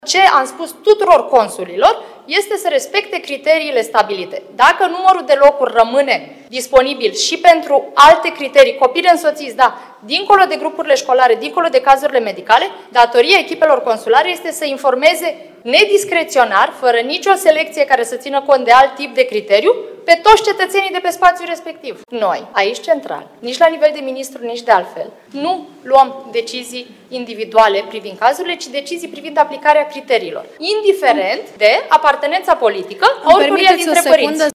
Ministra de Externe, Oana Țoiu: „Ce am spus tuturor consulilor este să respecte criteriile stabilite”